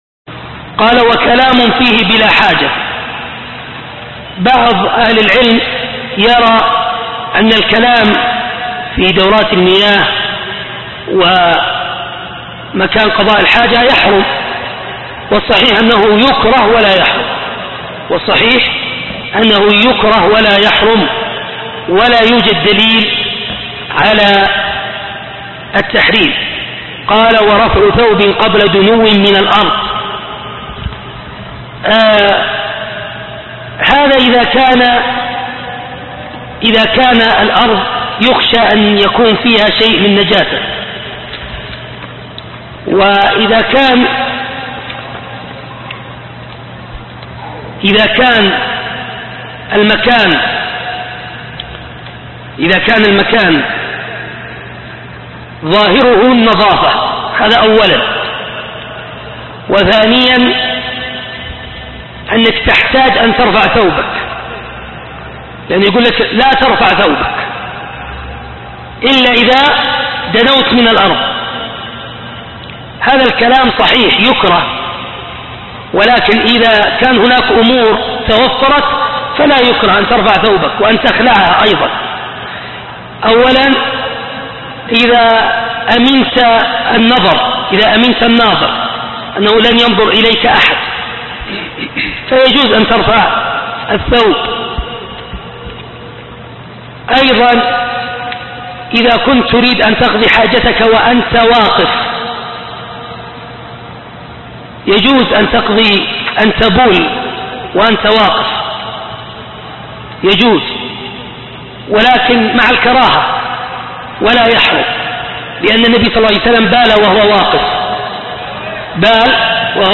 عنوان المادة حكم الكلام ورفع الثوب في دورة المياه (شرح كتاب أخصر المختصرات)